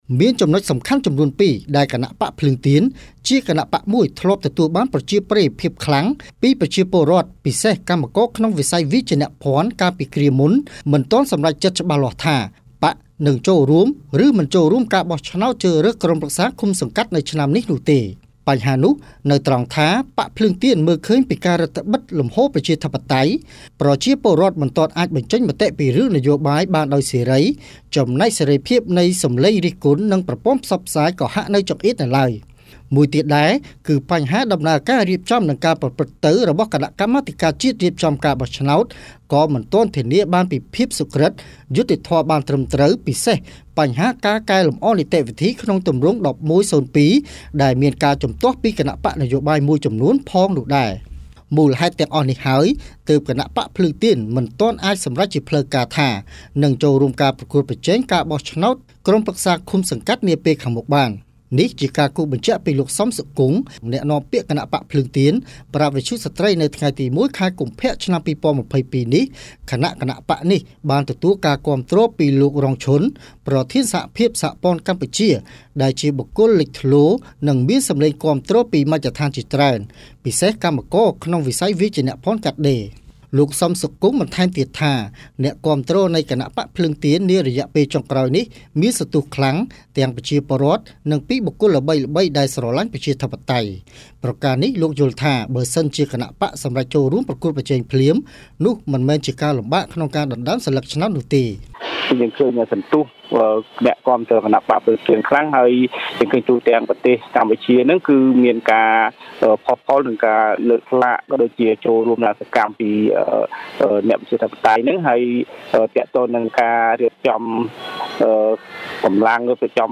រាយការណ៍ពីព័ត៌មាន